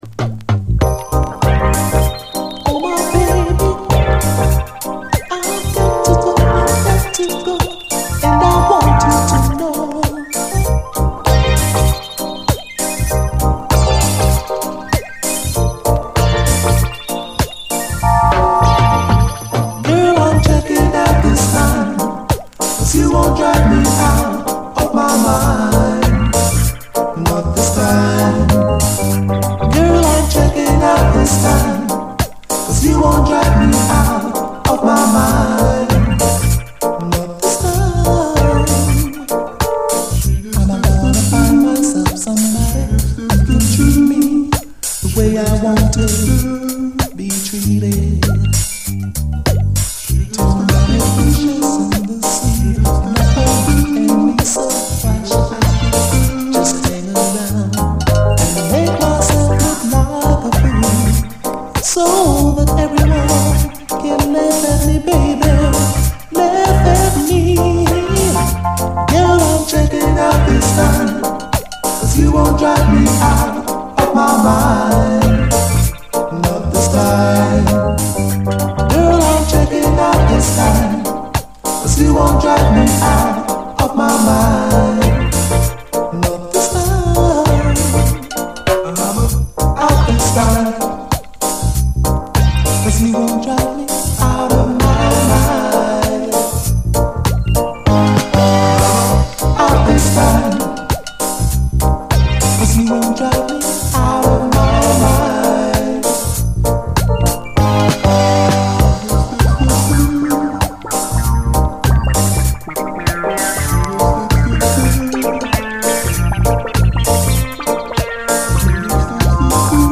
REGGAE
両面後半はダブに展開！